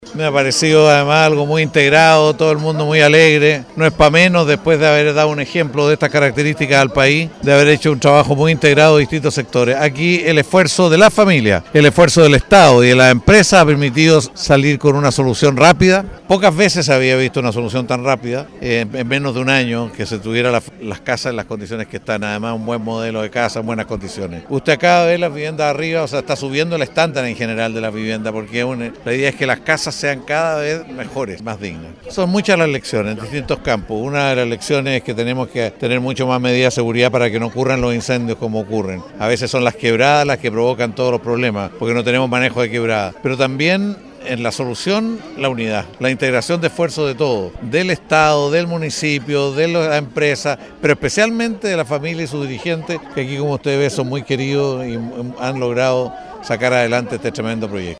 A 11 meses del incendio que afectó a la población Camilo Henríquez en Castro, el mediodía de este jueves, se realizó la entrega de las 49 casas construidas, con mobiliario completo, listas para ser habitadas, las que fueron inauguradas en una ceremonia que contó con la presencia del ministro de Vivienda y Urbanismo, Carlos Montes, autoridades locales y regionales, representantes de Desafío Levantemos Chile, de los gremios salmoneros, y la masiva presencia de los vecinos beneficiados.
Por su parte, el Ministro de Vivienda, Carlos Montes, destacó el alto estándar de construcción de las viviendas: